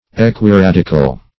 Search Result for " equiradical" : The Collaborative International Dictionary of English v.0.48: Equiradical \E`qui*rad"i*cal\a. [Equi- + radical.] Equally radical.
equiradical.mp3